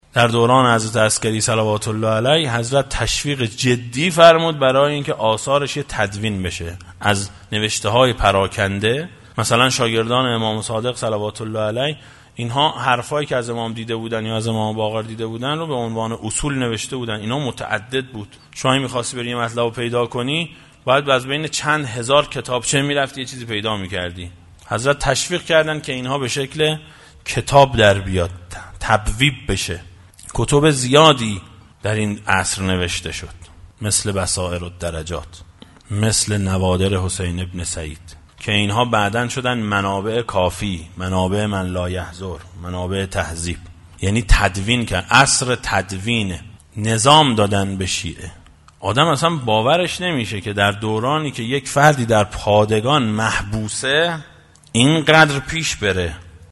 برگرفته از جلسه “گوشه هایی از زندگی امام حسن عسکری علیه السلام”